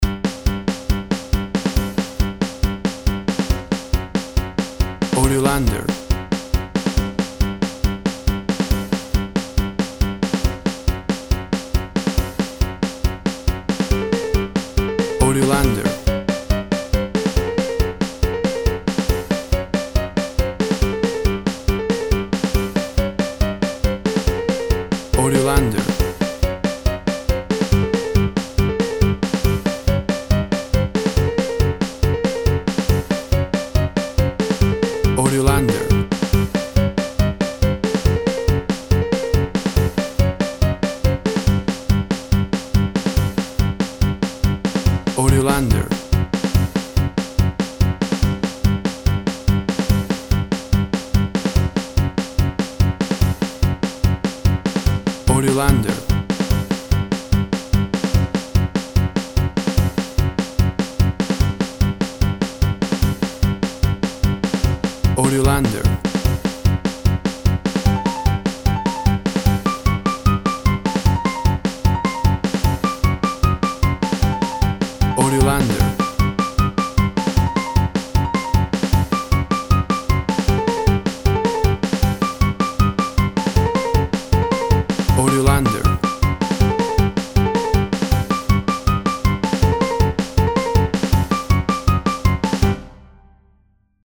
Cartoons chase music.
Tempo (BPM) 141